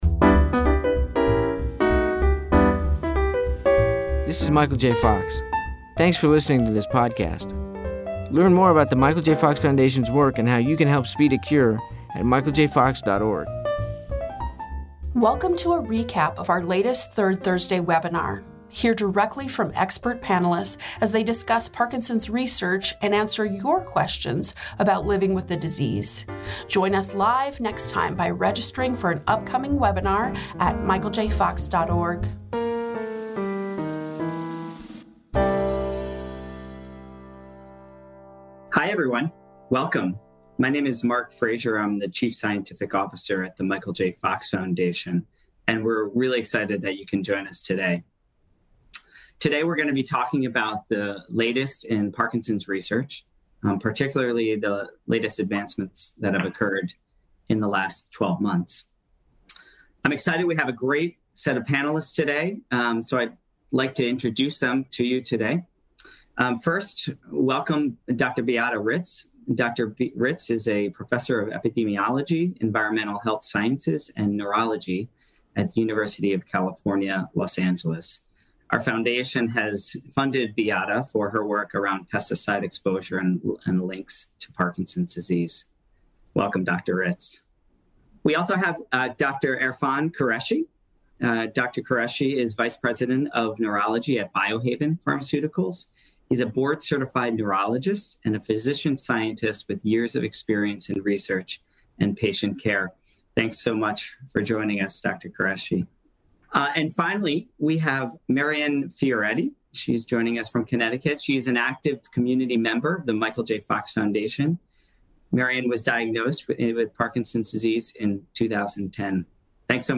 Hear expert panelists discuss the latest advancements in Parkinson’s research for our 2021 research review webinar.